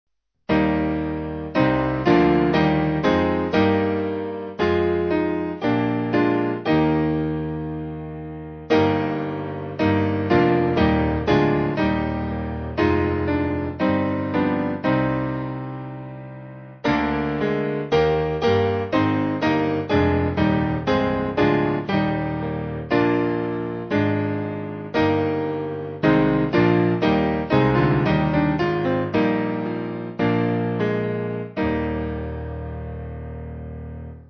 Simple Piano
(CM)   5/Eb